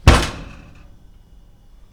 household
Oven Door Close